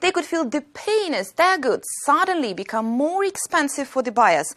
As so often when misunderstandings occur, we have a combination of several non-native pronunciation issues.